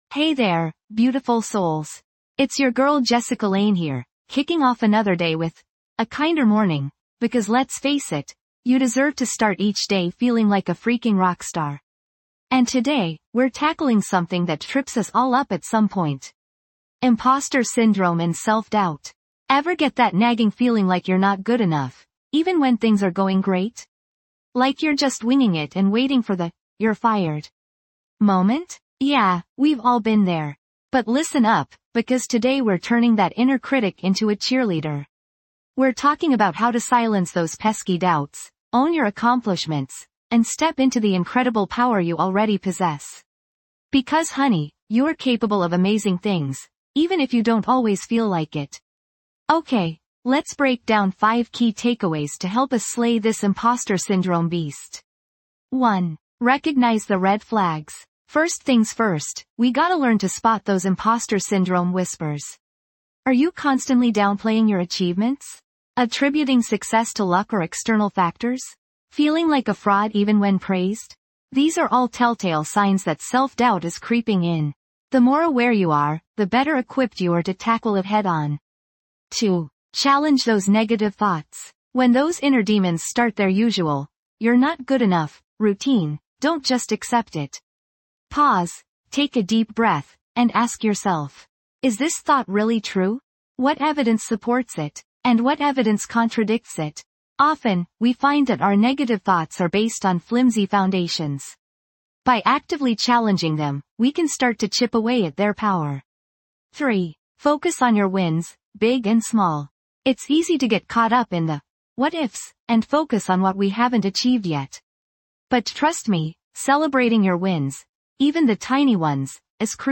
'A Kinder Morning | Compassionate Self Talk' offers guided meditations and encouraging affirmations designed to cultivate a kinder inner voice. Through daily practices, you'll learn to challenge negative thoughts, replace them with self-love and acceptance, and build resilience in the face of life's challenges.